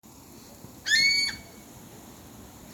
Saracuruçu (Aramides ypecaha)
Nome em Inglês: Giant Wood Rail
Província / Departamento: Tucumán
Condição: Selvagem
Certeza: Fotografado, Gravado Vocal